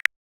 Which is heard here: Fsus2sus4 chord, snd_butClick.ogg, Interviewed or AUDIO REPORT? snd_butClick.ogg